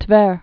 (tvĕr)